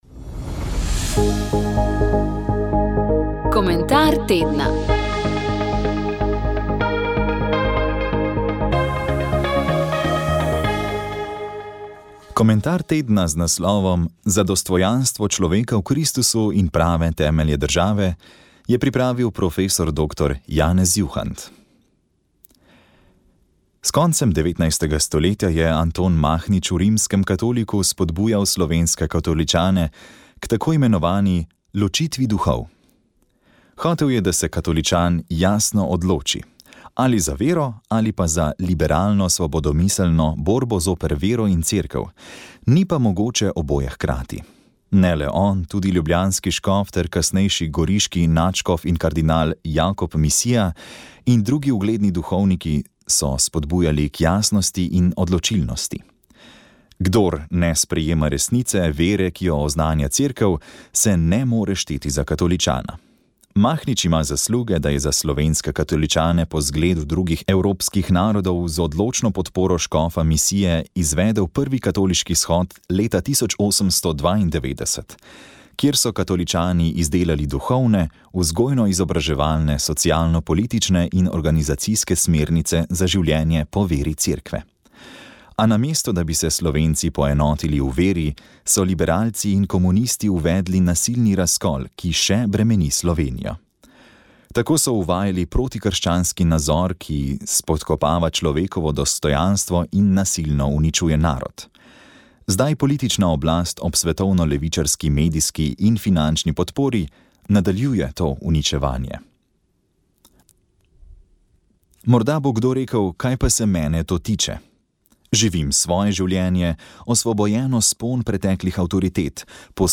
Molili so člani molitvene skupine iz Kranja.